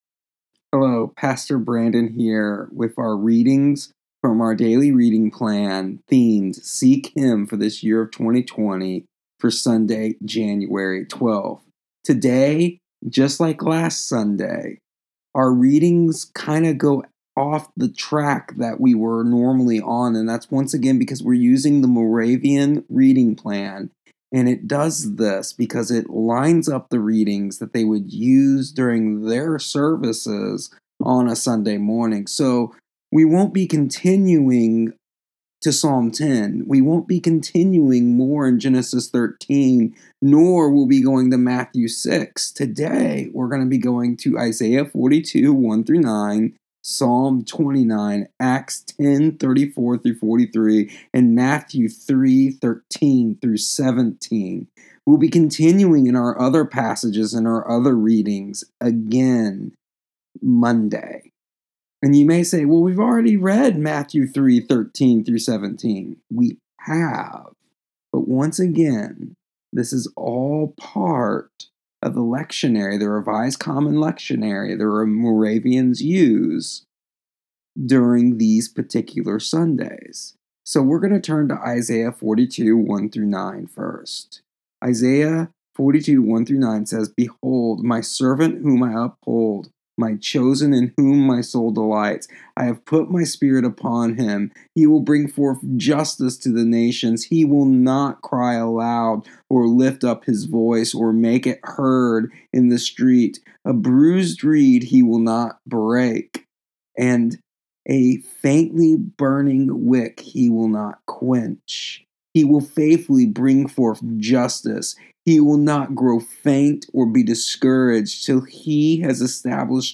Today’s readings are: